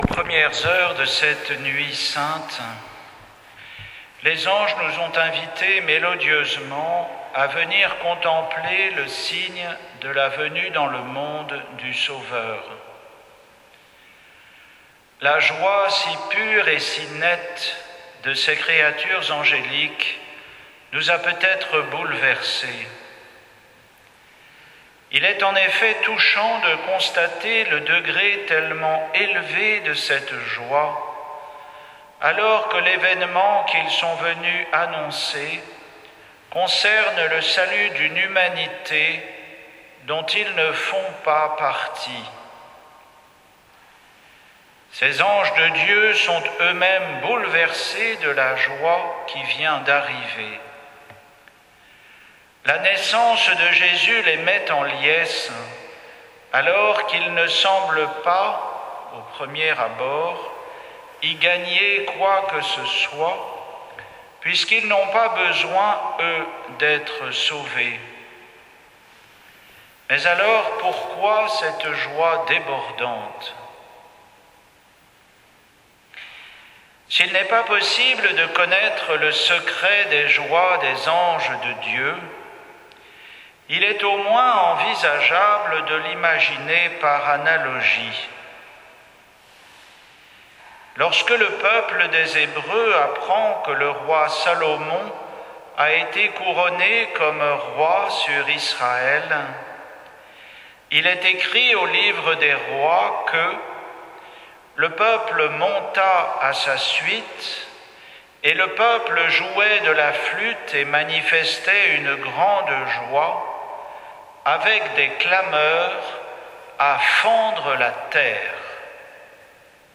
Homélie pour la messe du jour de Noël 2021